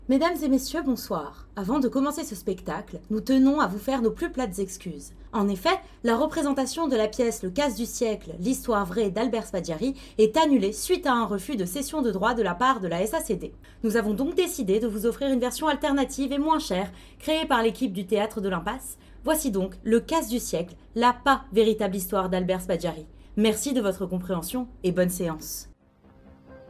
Voix off
20 - 60 ans - Soprano